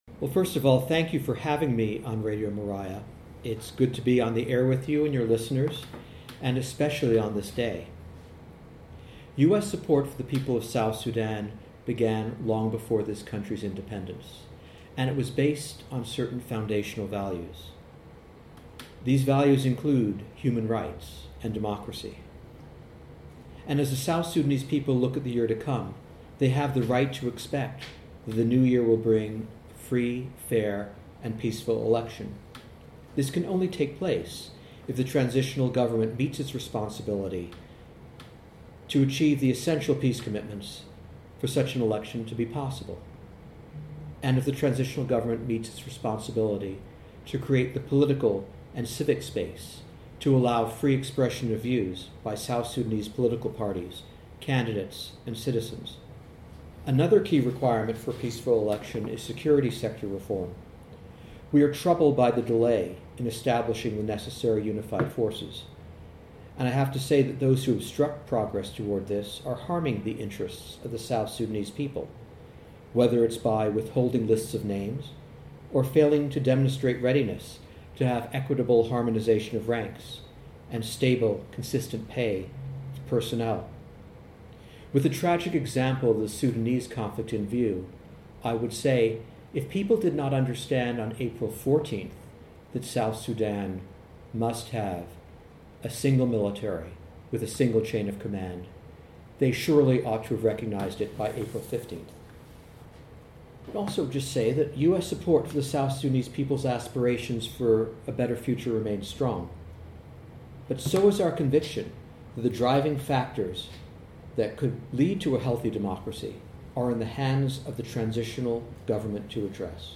International Democracy Day: An Interview with US Ambassador to South Sudan
Guest: Michael J. Adler - US Ambassador to South Sudan